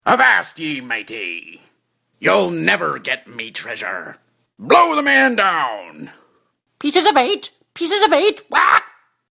voiceover
cartoons and games
pirateParrot.mp3